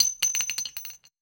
metahunt/weapon_ammo_drop_01.wav at master
weapon_ammo_drop_01.wav